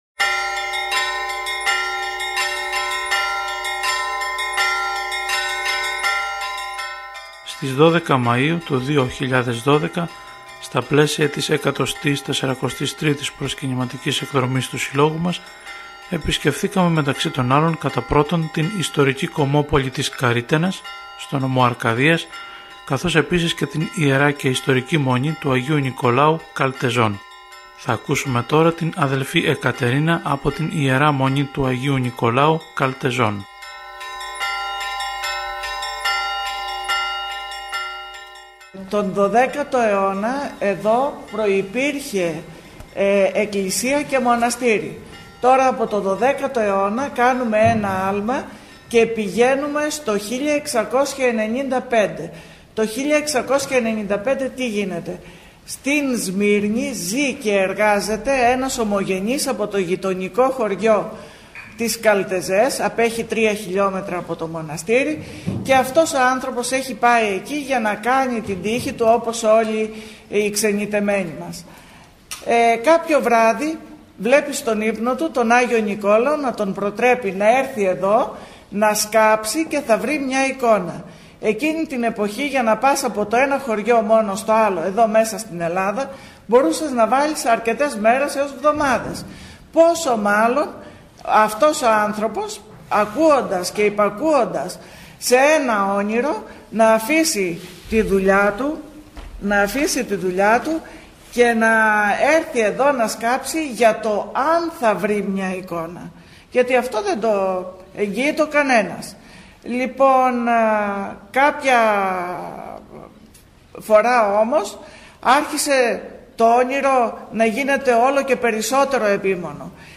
Παράβαλε και: Ηχητικό απόσπασμα από την επίσκεψή μας στην Ι. Μ. Αγ. Νικολάου Καλτεζών.